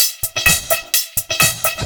Index of /VEE/VEE2 Loops 128BPM
VEE2 Electro Loop 288.wav